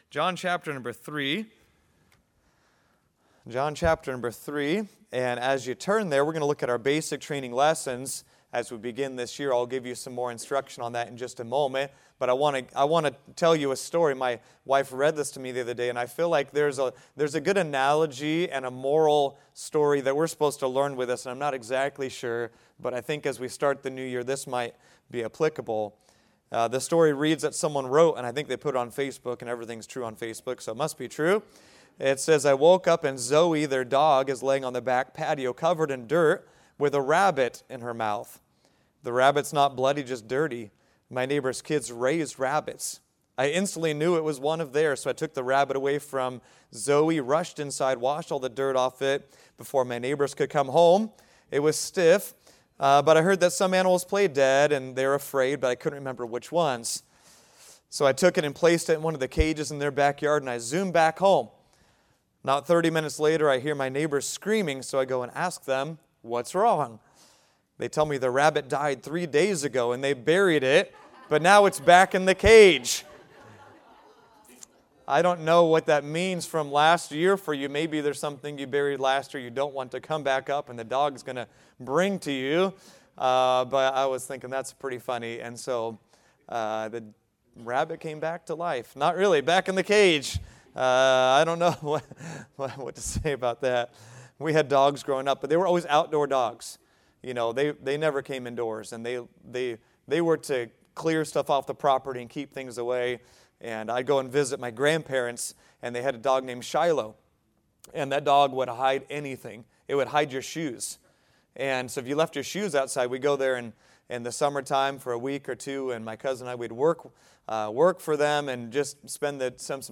Salvation | Sunday School – Shasta Baptist Church